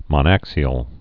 (mŏn-ăksē-əl)